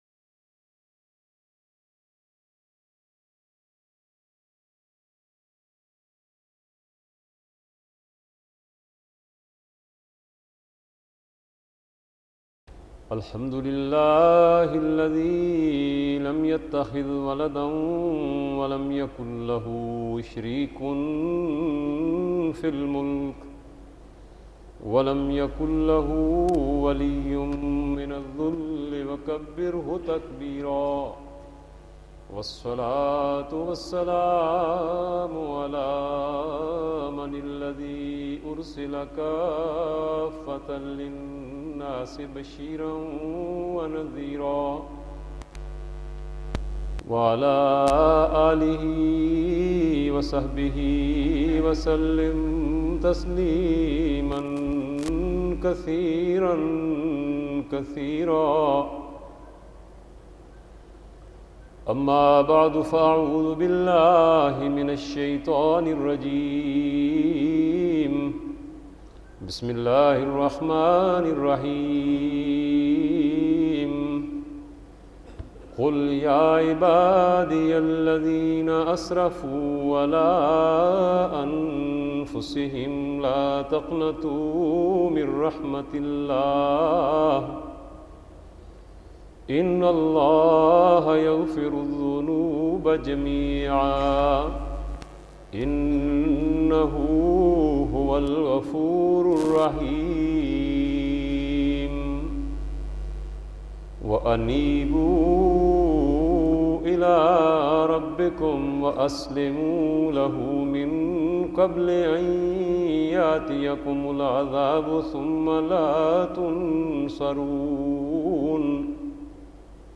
Khutba Juma
4067_Khutba-Juma.mp3